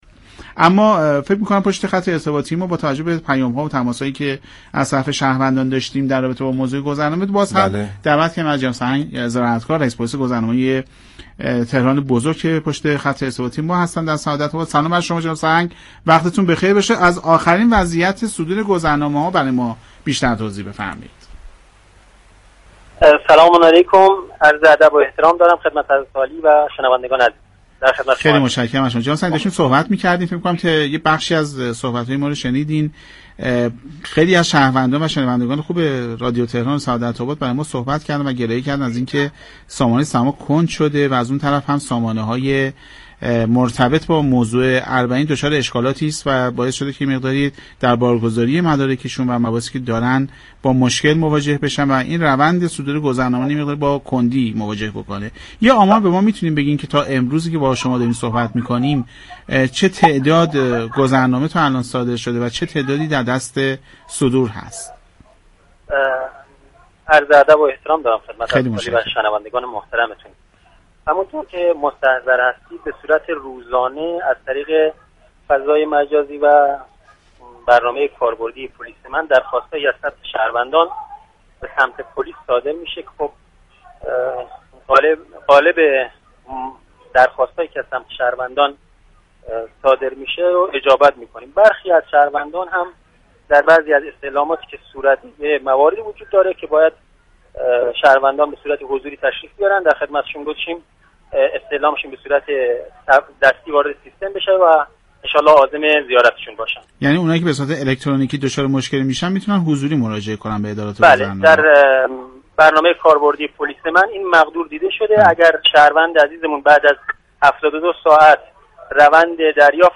به گزارش پایگاه اطلاع رسانی رادیو تهران؛ سرهنگ غلام‌حسین زراعتكار رئیس پلیس گذرنامه تهران بزرگ در گفت و گو با برنامه «سعادت آباد» در خصوص آخرین وضعیت صدور گذرنامه اظهار داشت: شهروندان به صورت روزانه از طریق فضای مجازی و برنامه كاربردی پلیس من درخواست‌هایی را برای دریافت گذرنامه ارسال می‌كنند كه بیشتر این درخواست‌ها پاسخ داده می‌شود.